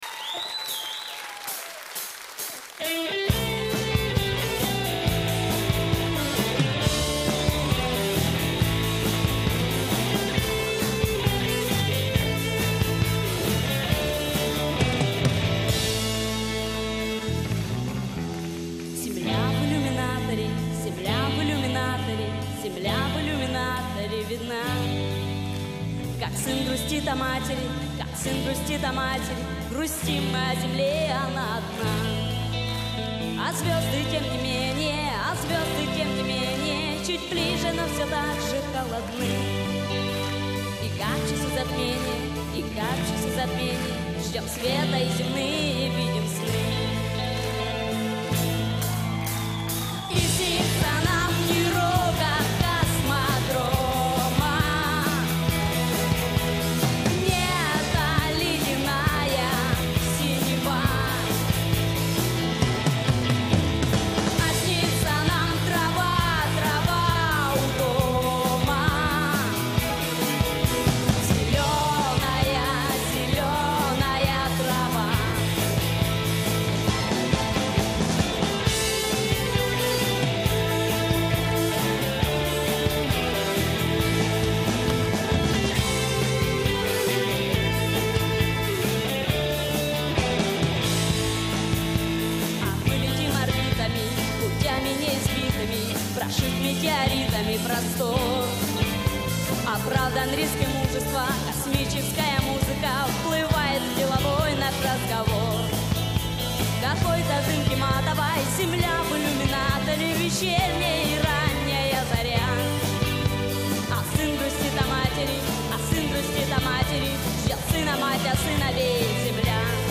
Только концертные записи есть